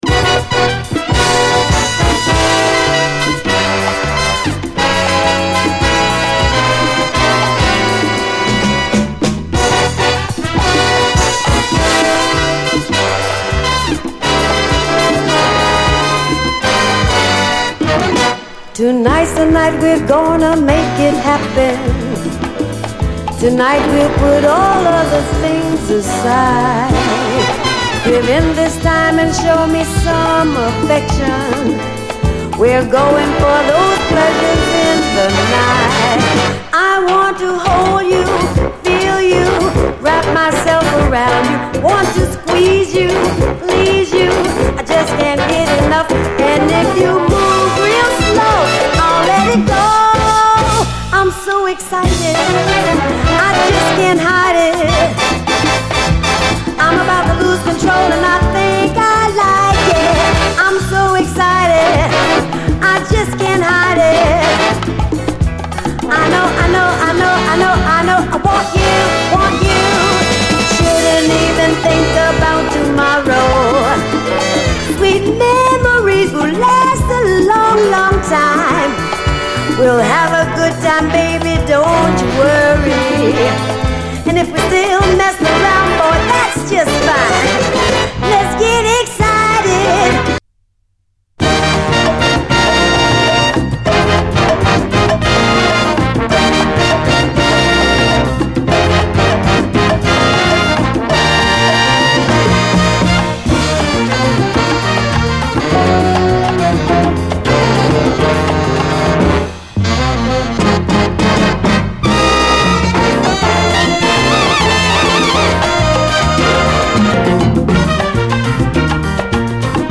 SALSA, JAZZ, LATIN
ロマンティック・サルサ〜ラテン・ジャズの金字塔、と称したい、全編最高の一枚！